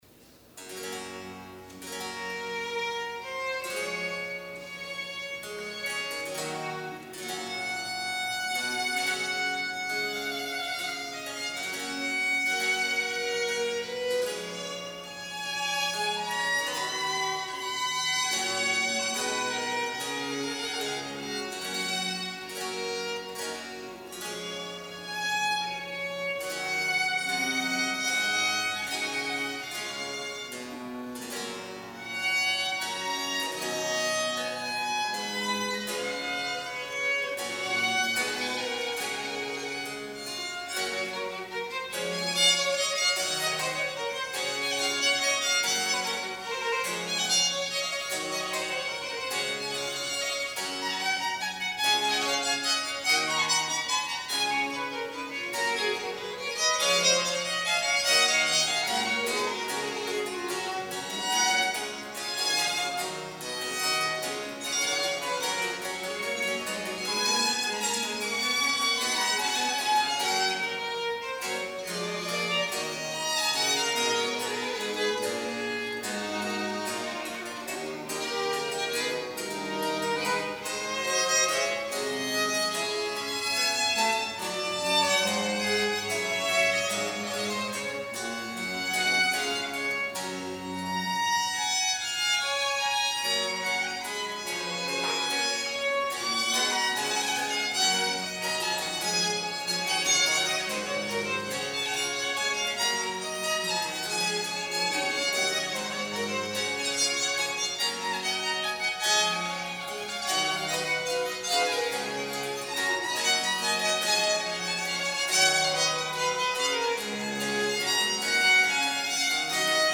Ciacona in G Major, Arcangelo Corelli, 1653-1713; Jefferson Baroque
Performers:  Jefferson Baroque